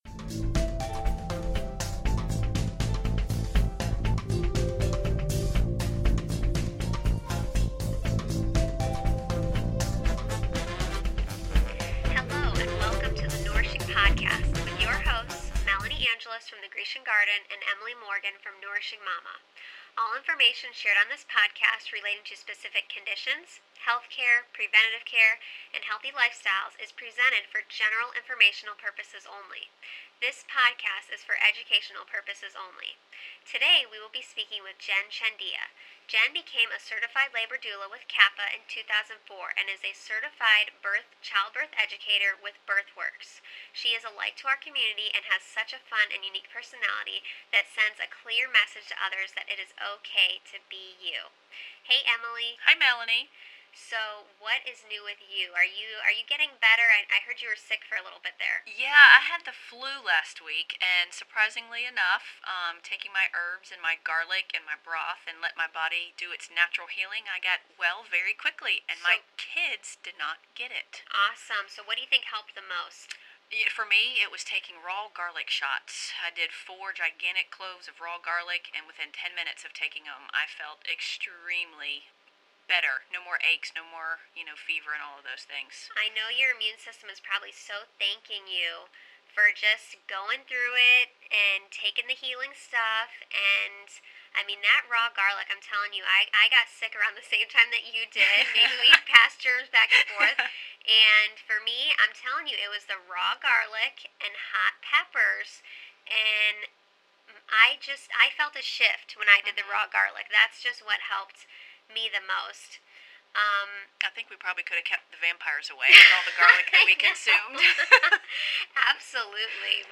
Here is our latest Pod Cast with local Doula
doula.mp3